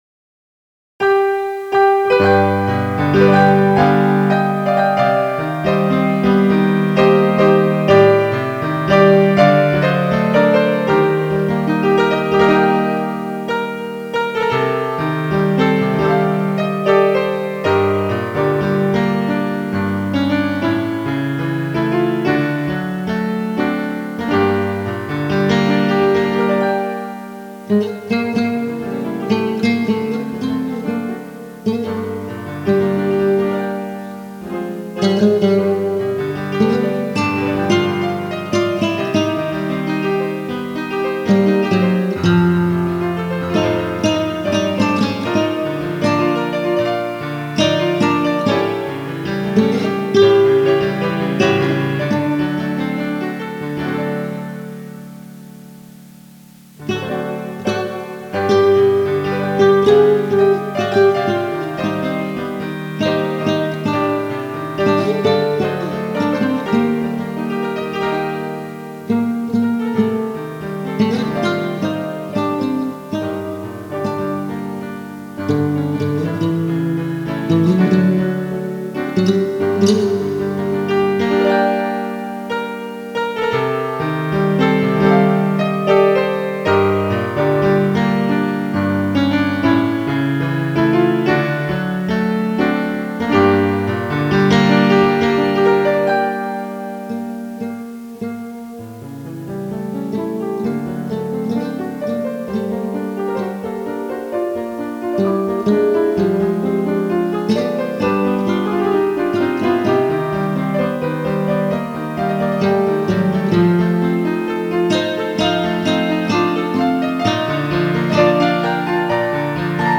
• 5124 Гитара с оркестром